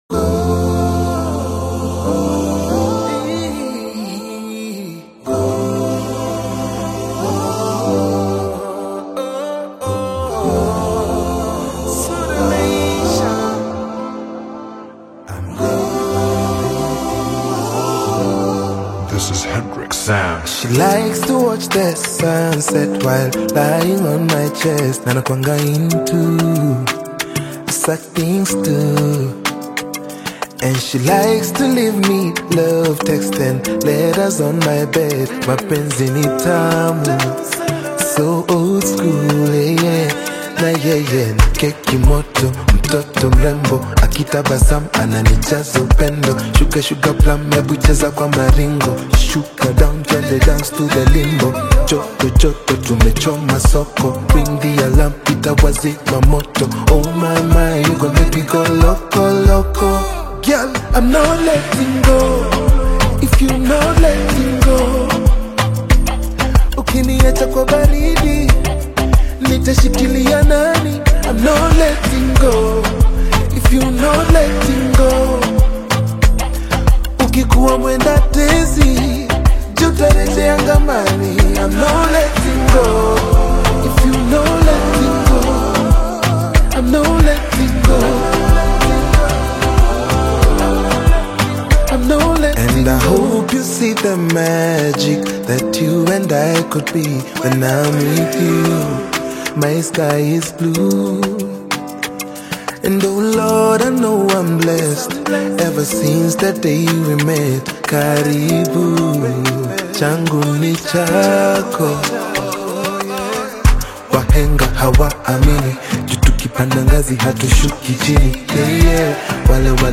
Afro-Pop/R&B single